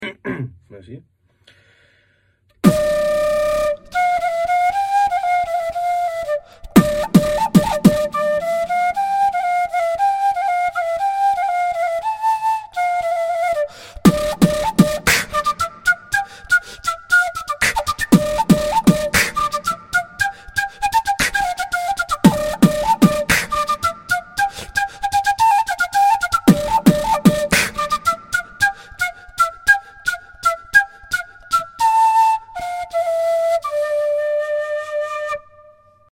Cartoon Voice Actor Part 6